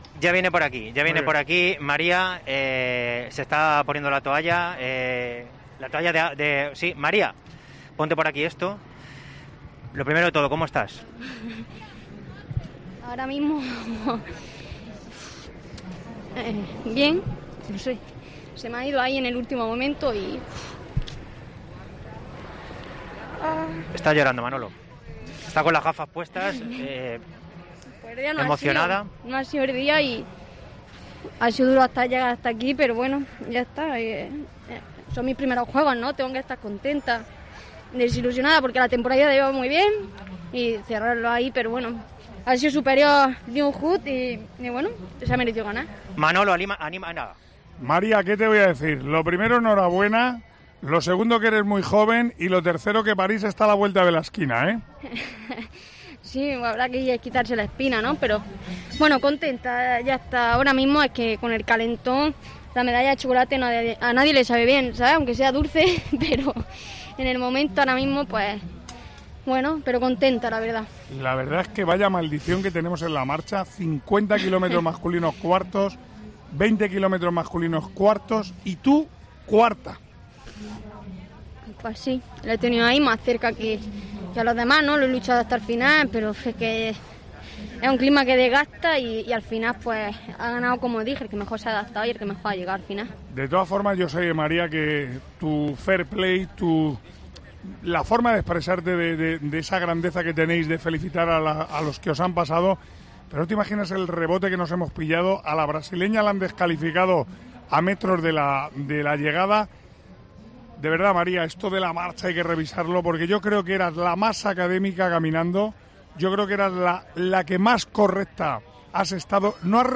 Las lágrimas de María Pérez tras quedar cuarta en los 20 km marcha: "Lo he luchado hasta el final"
La deportista comentó en COPE sus sensaciones tras participar por primera vez en unos Juegos Olímpicos.